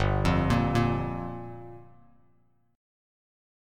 Gm7 Chord
Listen to Gm7 strummed